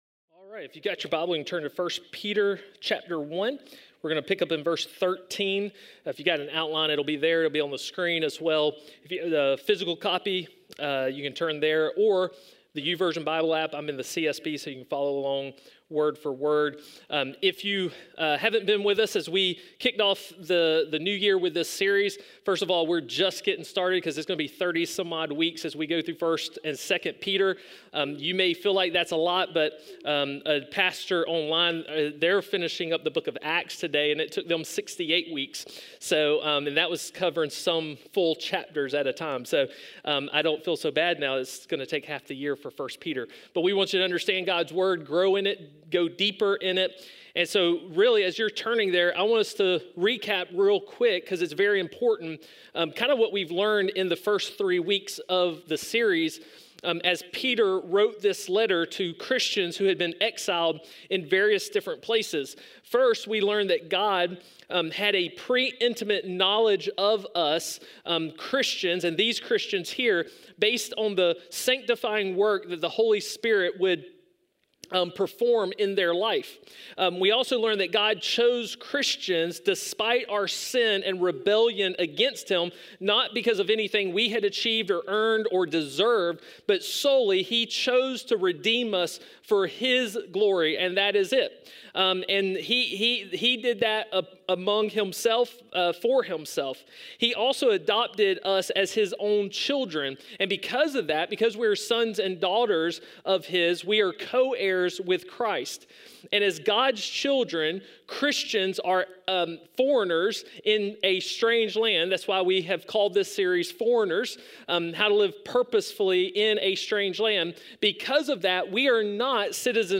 A message from the series "Foreigners."